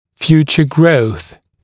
[‘fjuːʧə grəuθ][‘фйу:чэ гроус]будущий рост